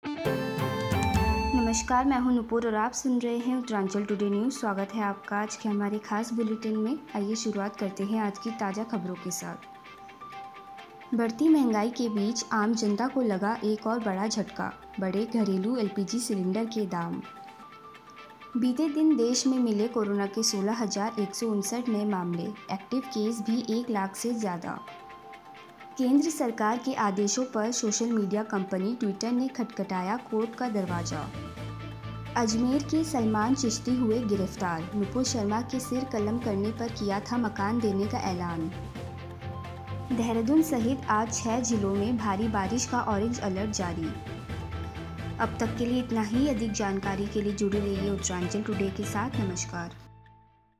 फटाफट समाचार(6-7-2022) सुनिए अब तक की कुछ ख़ास खबरे